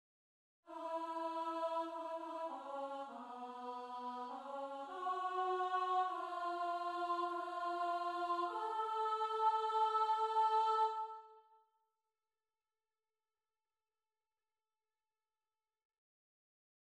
Key written in: F# Major
Type: SATB
Learning tracks sung by